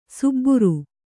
♪ subburu